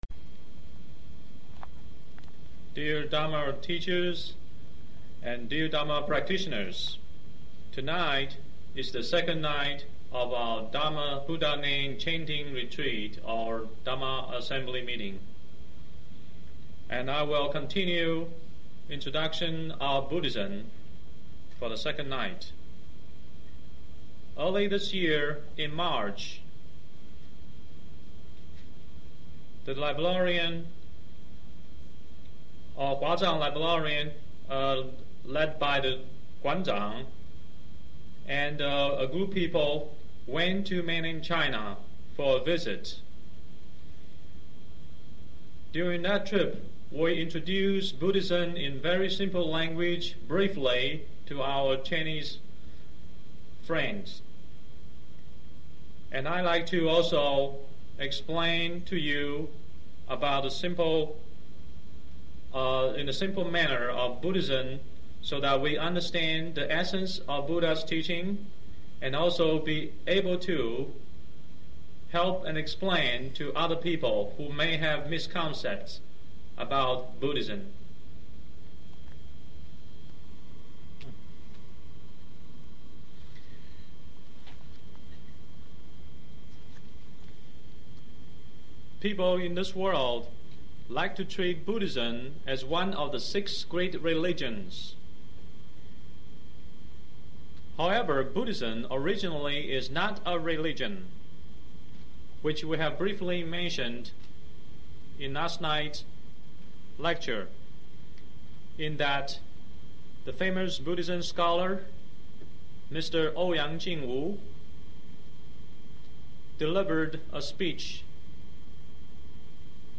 93年達拉斯佛七講話(英文)